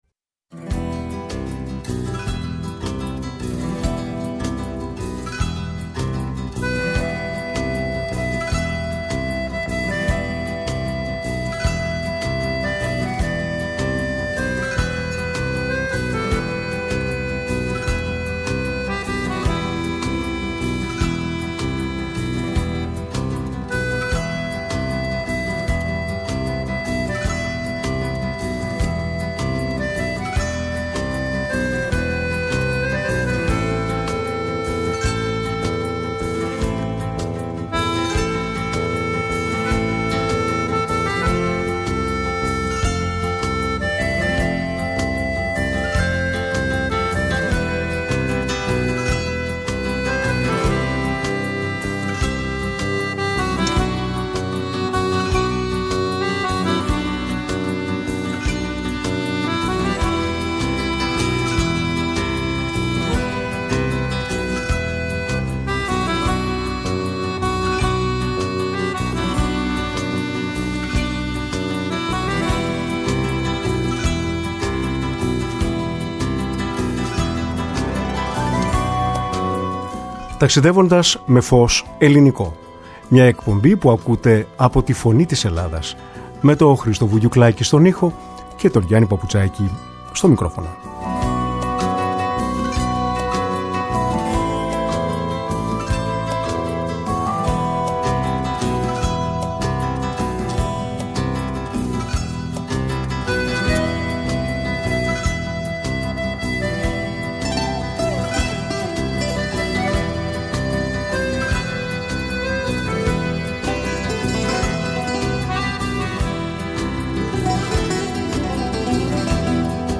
σε μια συζήτηση για μουσική, θέατρο, συναίσθημα και λογική!